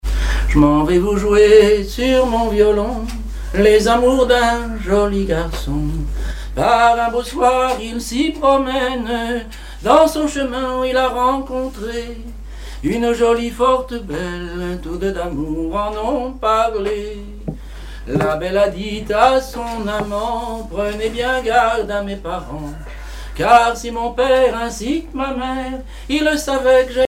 Mémoires et Patrimoines vivants - RaddO est une base de données d'archives iconographiques et sonores.
Chansons et commentaires
Pièce musicale inédite